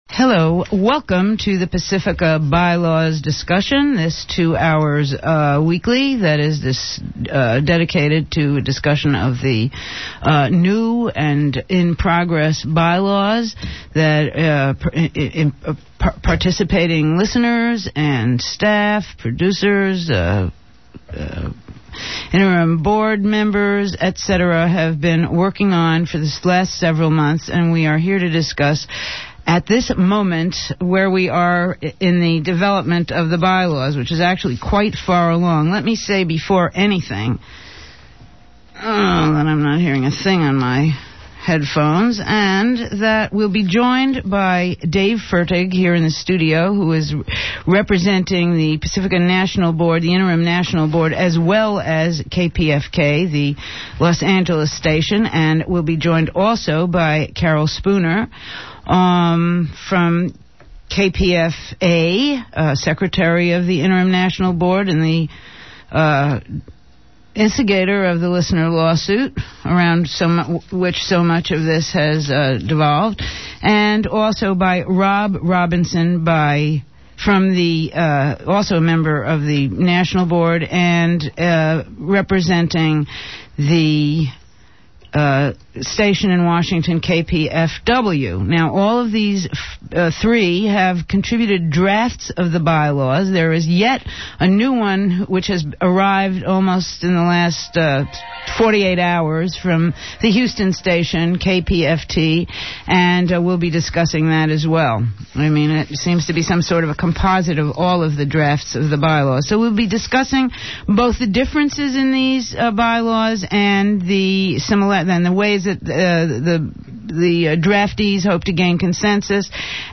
Second Hour (4pm) : Listener phone calls.